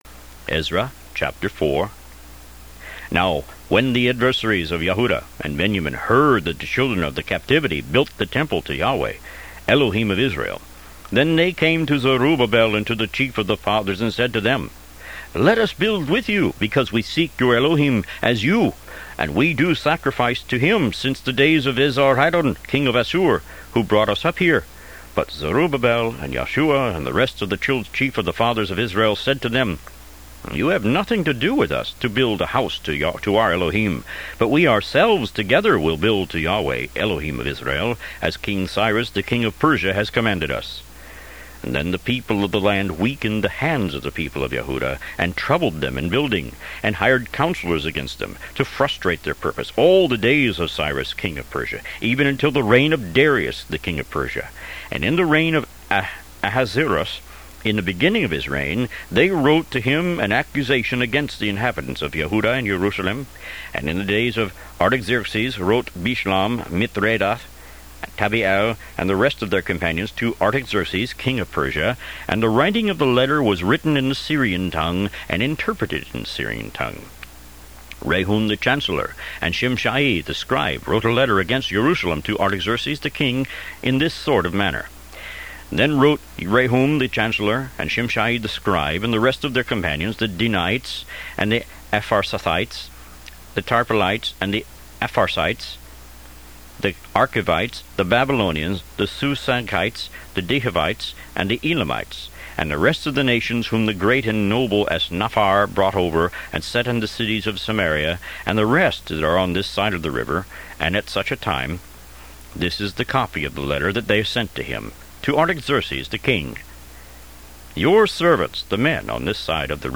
Root > BOOKS > Biblical (Books) > Audio Bibles > Tanakh - Jewish Bible - Audiobook > 15 Ezra